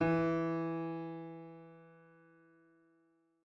piano-sounds-dev
SoftPiano
e2.mp3